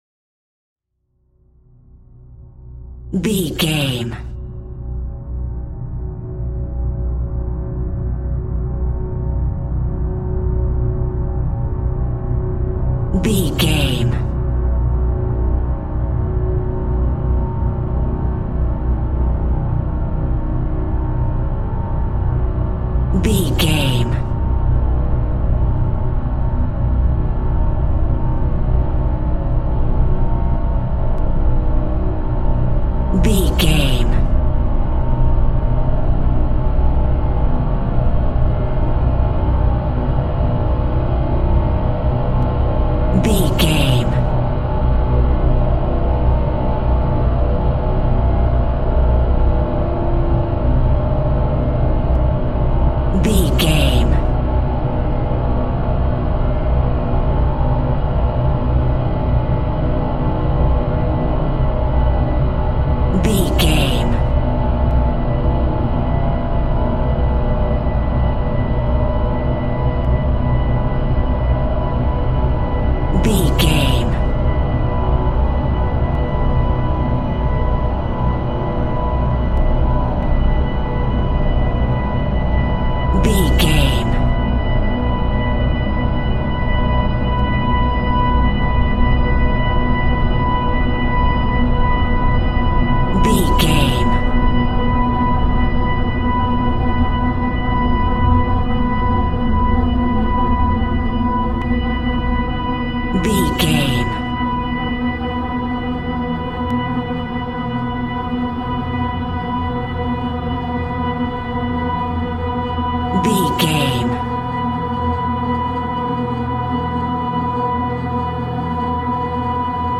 Scary Music for October.
In-crescendo
Aeolian/Minor
Slow
tension
ominous
dark
eerie
synthesiser
Synth Pads
atmospheres